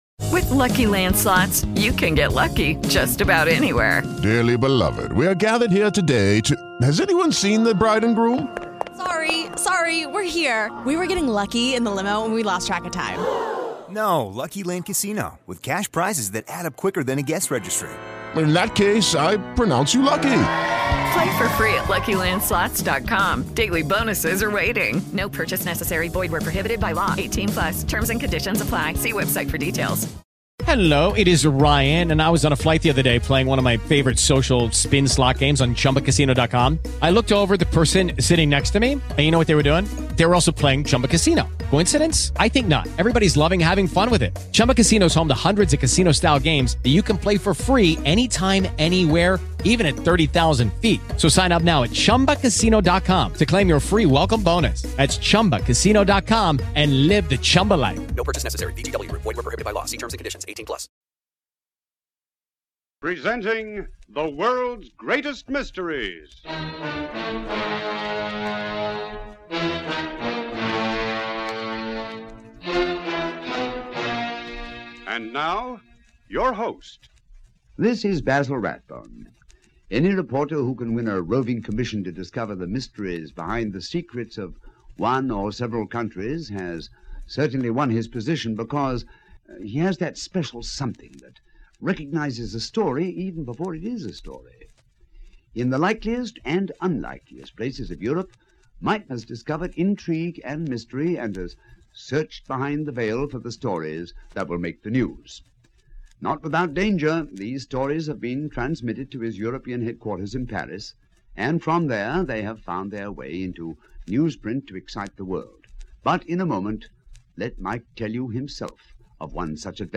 Europe Confidential, a classic from the golden age of radio!